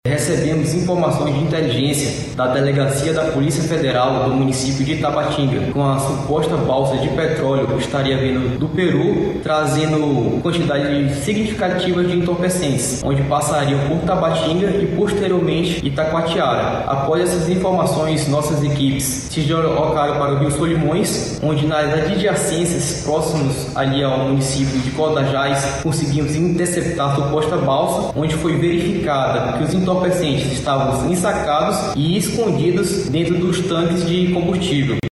SONORA-1-APREENSAO-DROGA-CODAJAS-.mp3